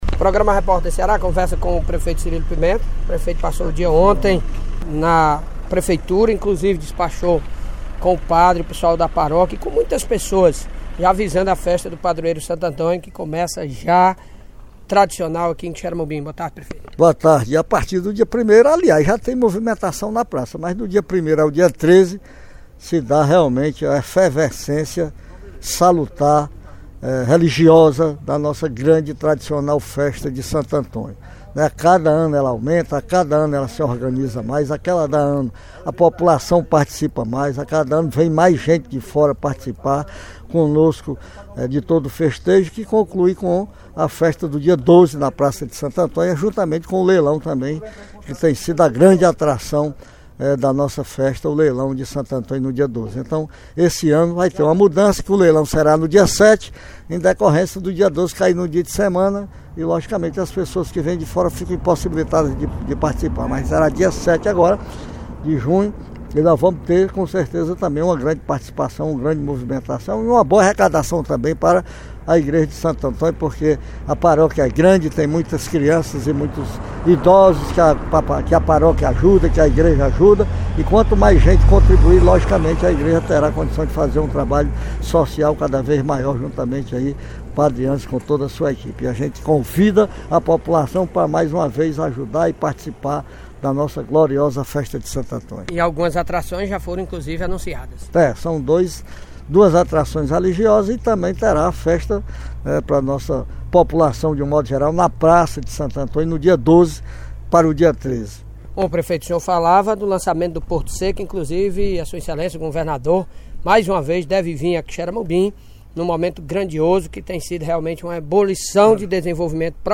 O governador do Ceará, Elmano de Freitas, visitará Quixeramobim em 4 de julho para acompanhar o início da construção do Porto Seco Senador José Dias de Macêdo. A informação foi revelada pelo prefeito Cirilo Pimenta, durante entrevista exclusiva à Rádio Campo Maior AM 840, nesta quinta-feira, 22.